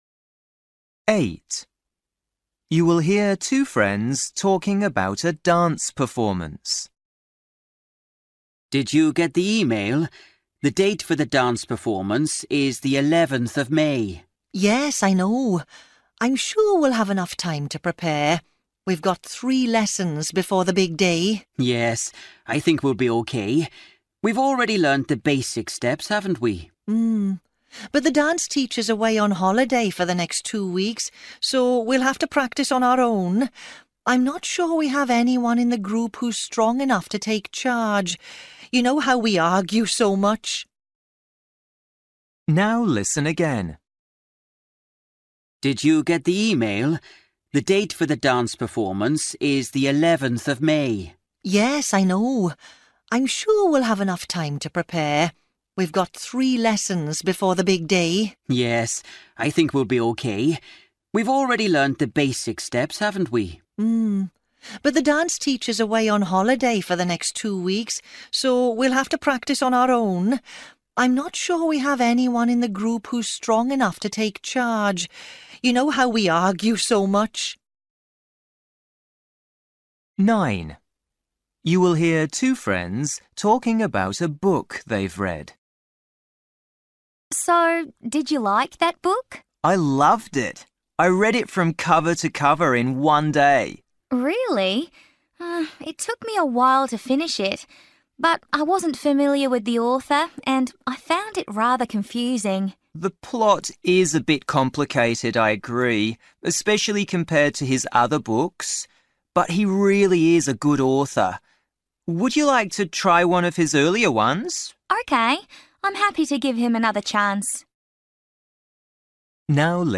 Listening: everyday short conversations
You will hear two friends talking about a dance performance.
10    You will hear a man telling a friend about a football match.
11   You will hear two friends talking about a visit to the dentist.